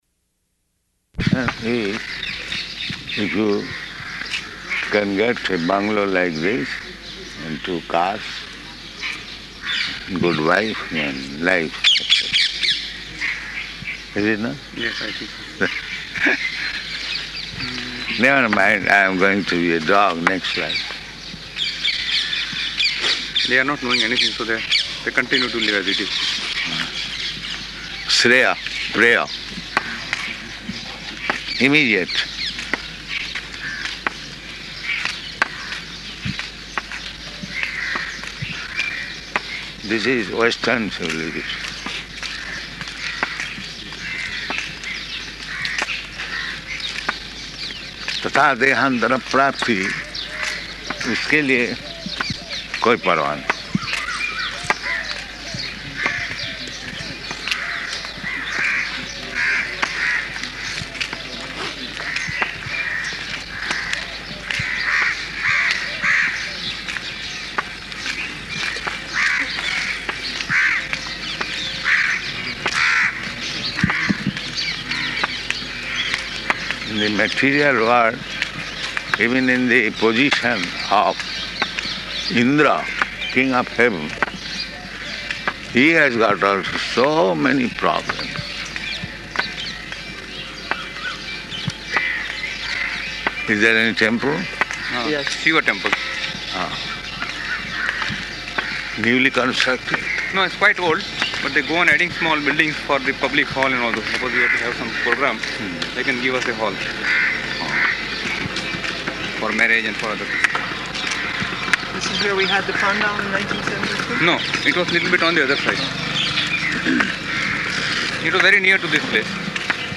Location: Ahmedabad